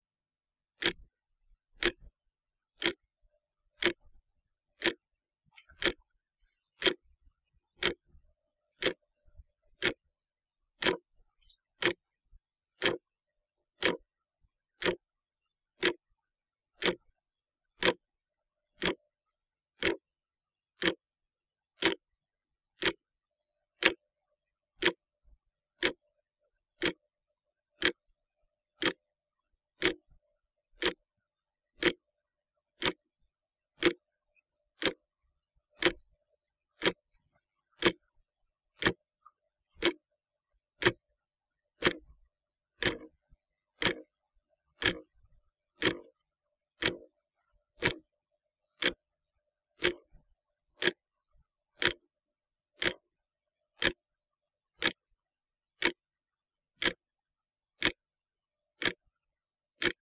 Clock ticking
This is mostly trying out my new recording equipment. It's my clock ticking -- might be usefull for some ambiental effects.
clock.ogg